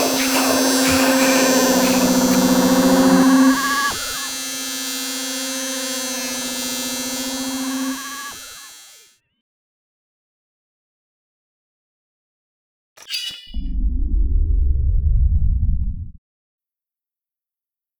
shock.wav